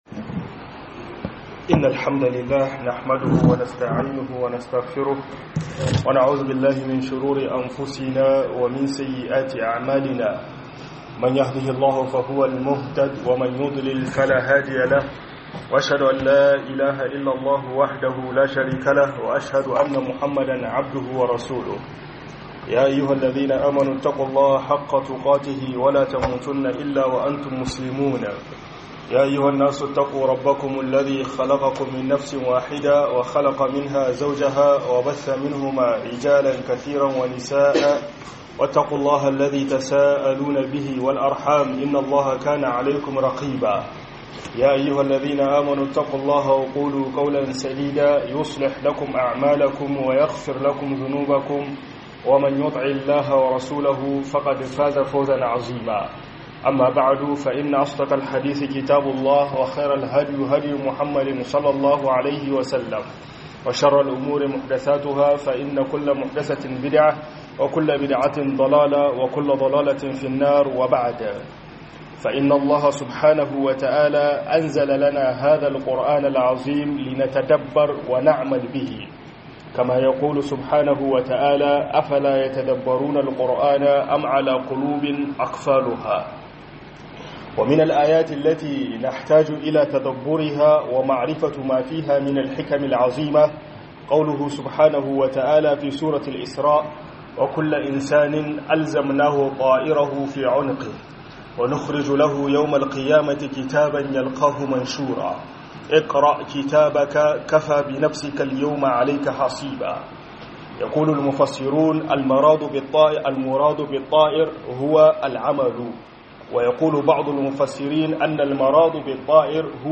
AL'QUR'ANI - HUDUBA